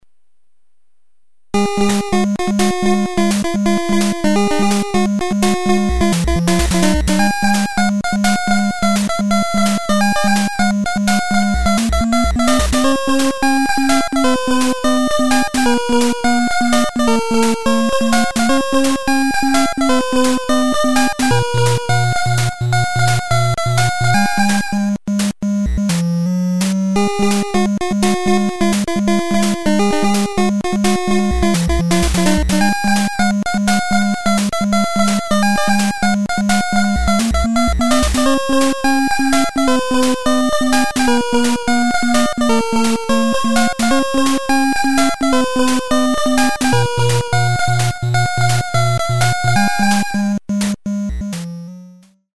Even the music is upbeat: